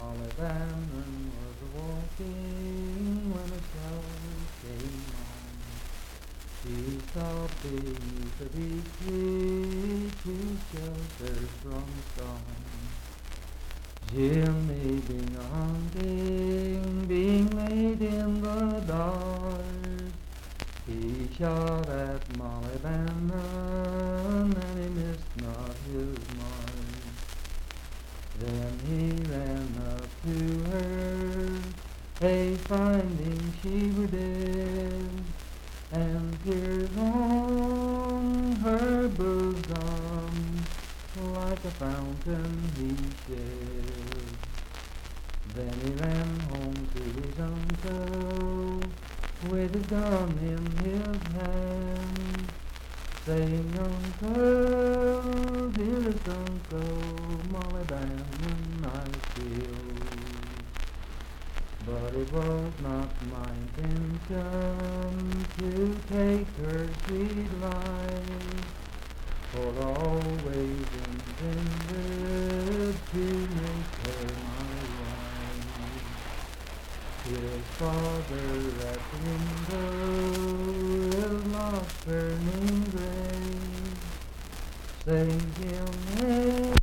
Unaccompanied vocal music
Verse-refrain 7d(4).
Voice (sung)
Pocahontas County (W. Va.), Marlinton (W. Va.)